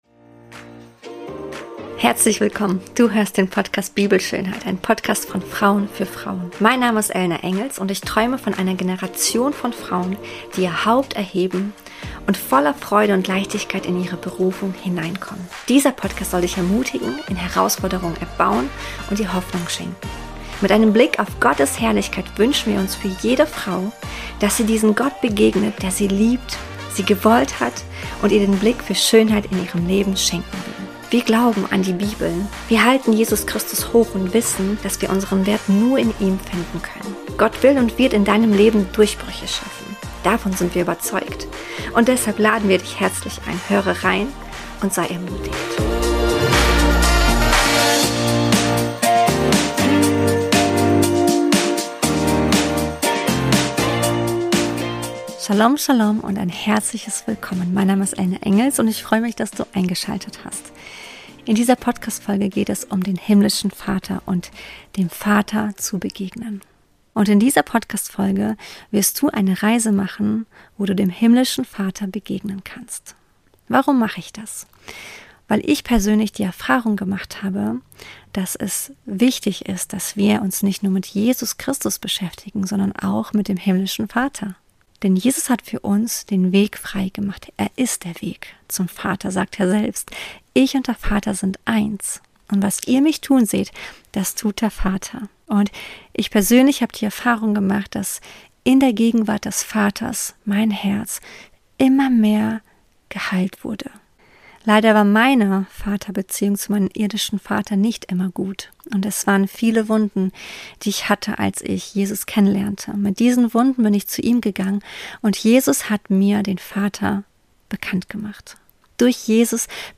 Meditation: Dem Vater begegnen – Mit Jesus ins Herz Gottes ~ BIBELSCHÖNHEIT Podcast